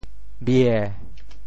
“廟”字用潮州话怎么说？
庙（廟） 部首拼音 部首 广 总笔划 15 部外笔划 12 普通话 miào 潮州发音 潮州 bhiê7 文 中文解释 庙 <名> (形声。